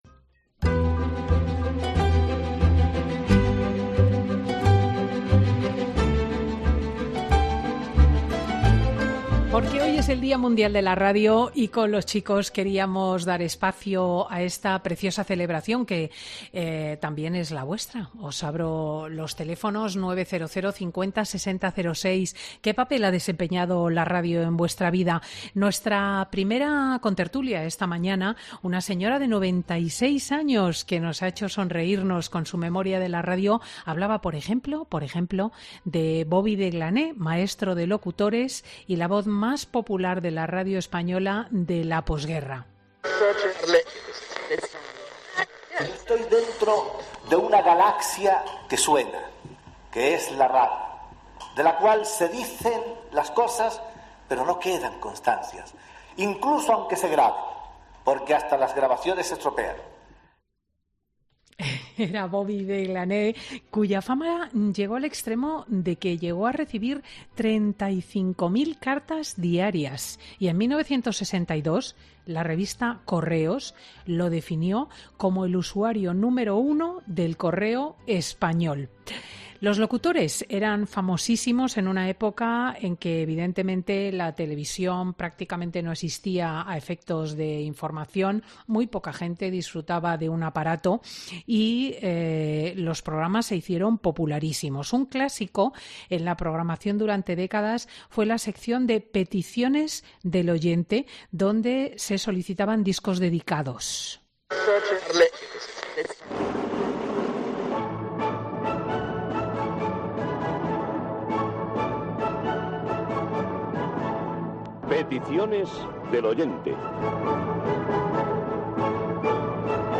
AUDIO: La tertulia de chicos en Fin de Semana con Cristina recuerda los eventos en los que la radio ha acompañado y cómo son los recuerdos de los...